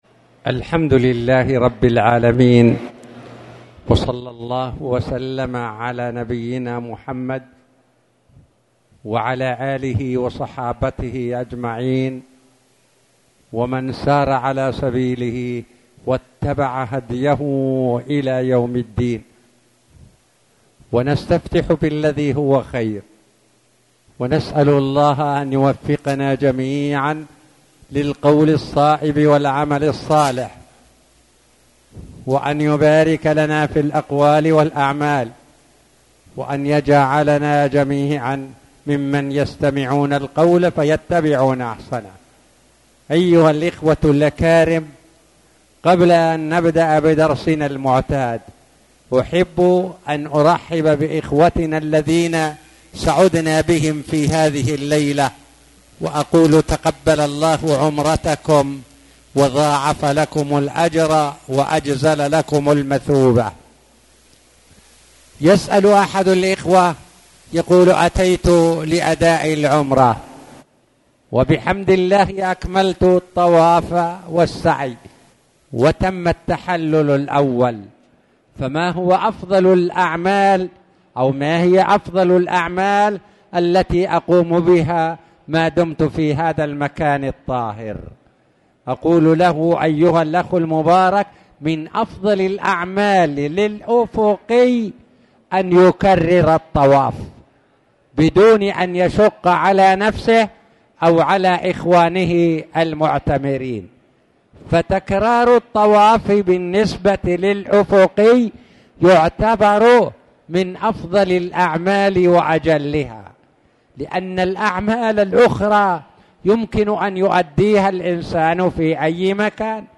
تاريخ النشر ١٨ شوال ١٤٣٨ هـ المكان: المسجد الحرام الشيخ